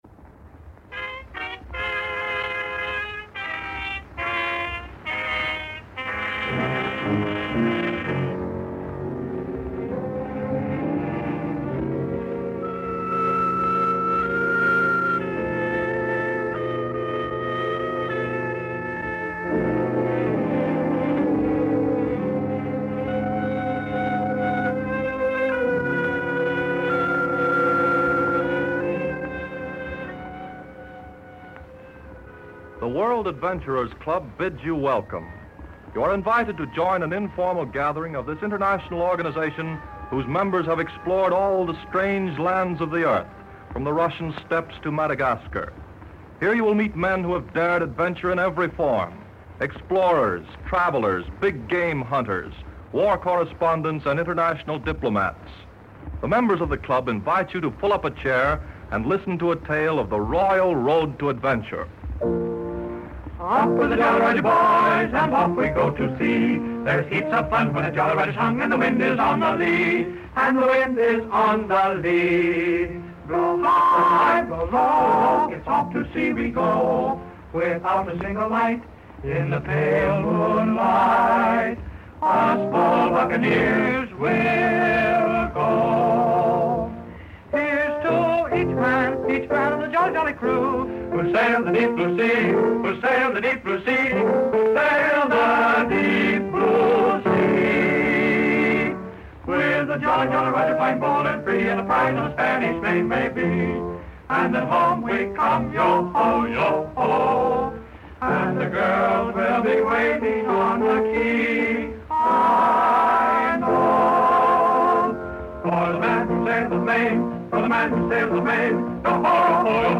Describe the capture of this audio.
The radio series, which first aired in 1932, offered listeners an escape to exotic locales and thrilling escapades during a time when the world was grappling with the Great Depression.